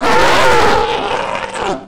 death2.wav